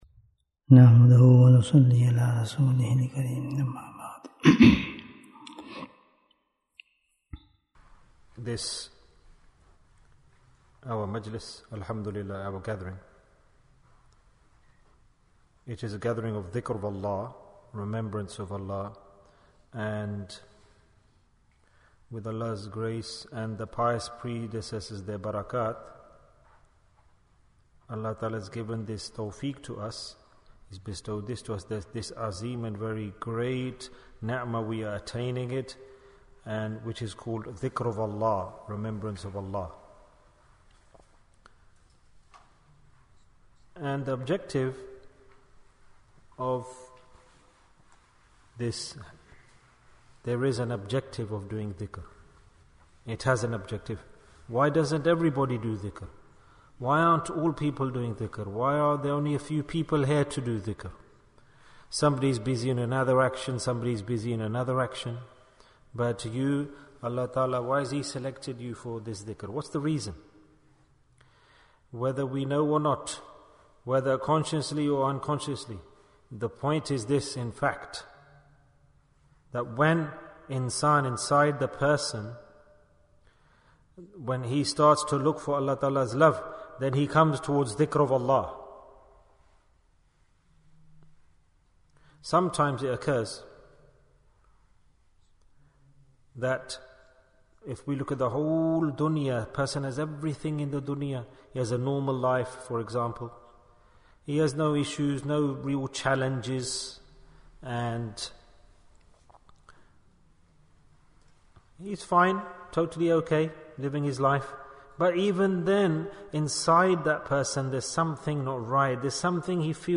Bayan, 40 minutes8th April, 2023